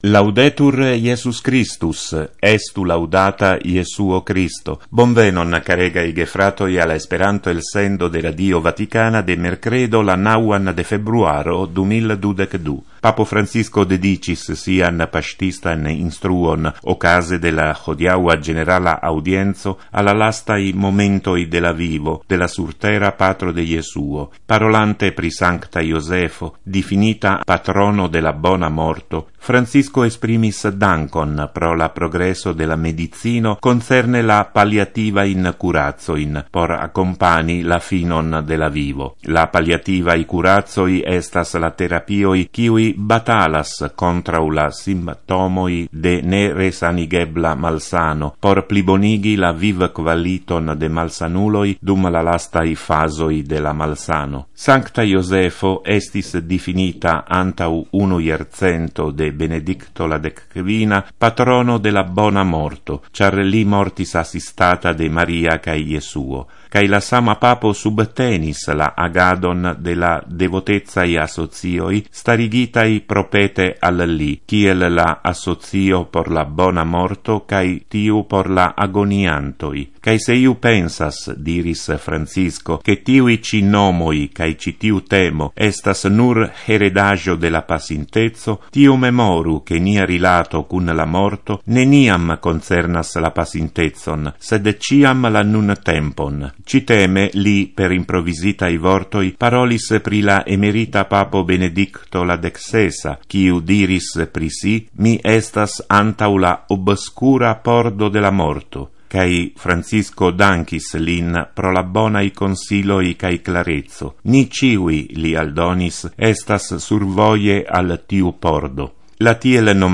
Intervjuo: Papo Ratzinger neniam provis kaŝi la malbonon en la eklezio.